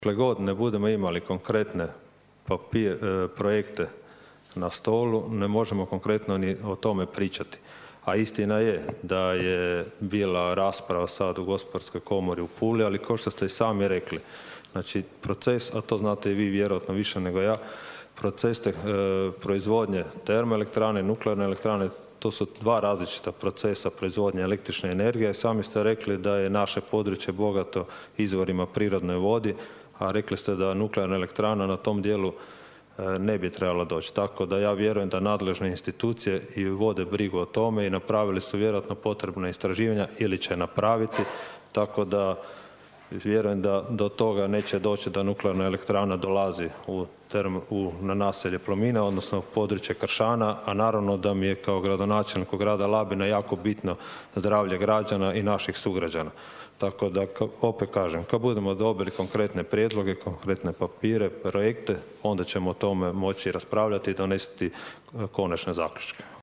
Gradonačelnik Blašković je odgovorio: (